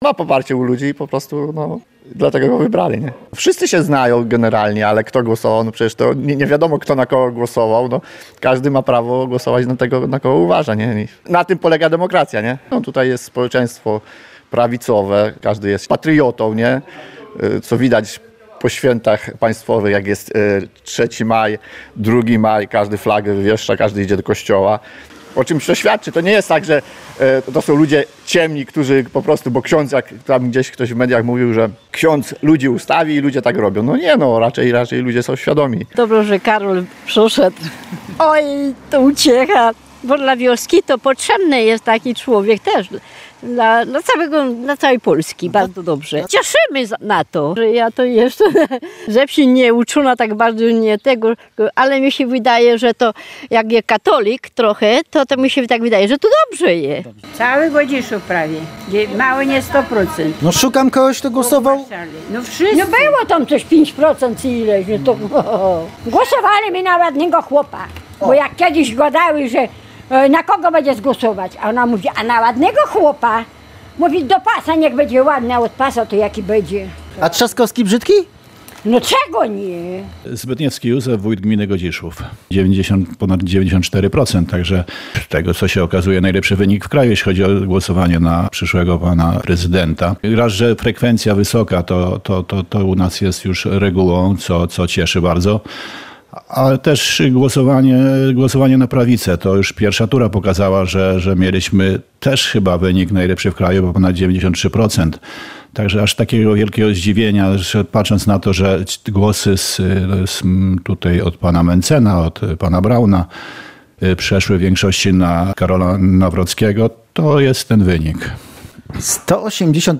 – Każdy ma prawo głosować na tego, na kogo uważa. Na tym polega demokracja – mówi w rozmowie z Radiem Lublin mieszkaniec gminy.